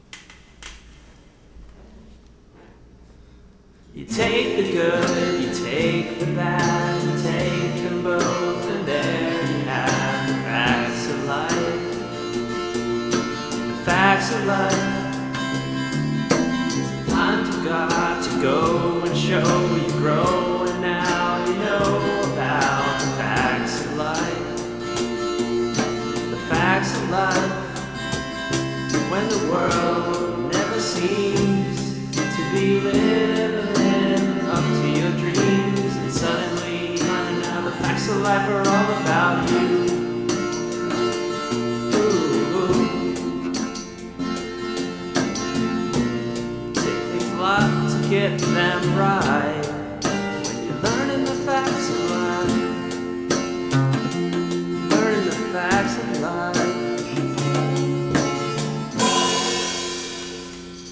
pensive song